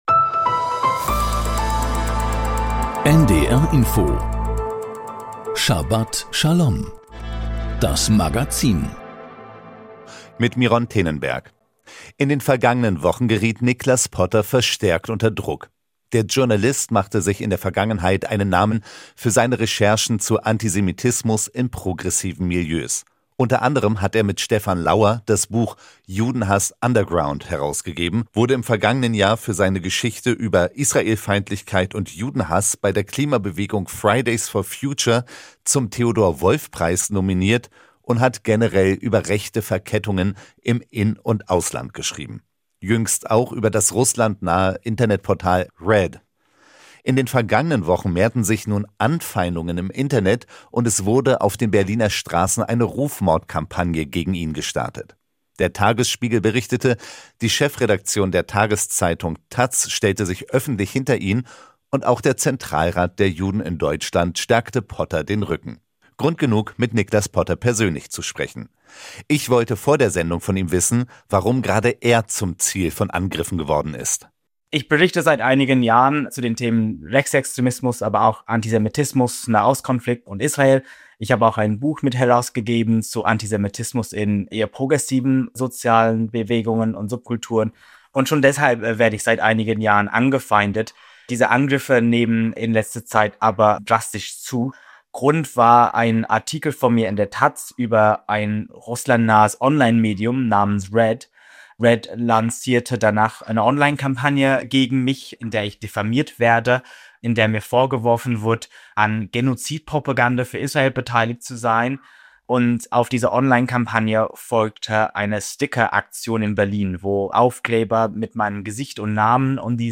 Das Magazin Schabat Schalom berichtet aus dem jüdischen Leben mit Nachrichten, Interviews, Berichten und Kommentaren. Dazu die Wochenabschnittsauslegung der Thora.